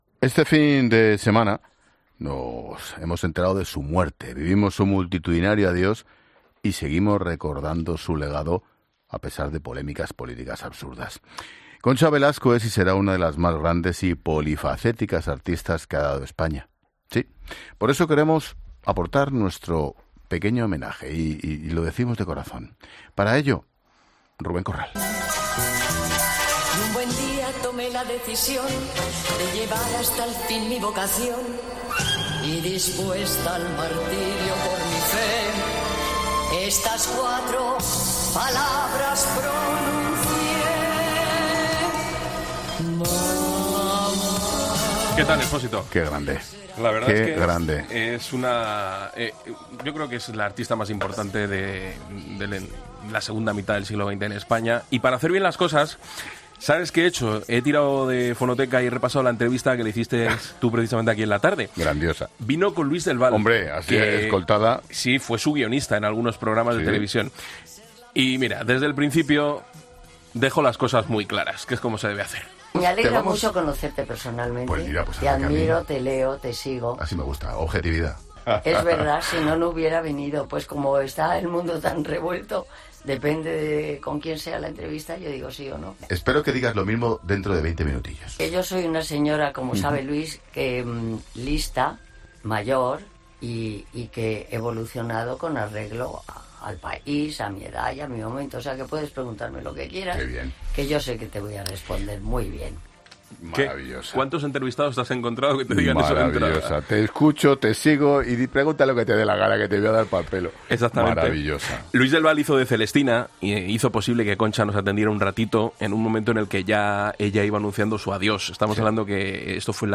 Escucha el homenaje de Expósito a Concha Velasco y lo que le dijo en su última entrevista en COPE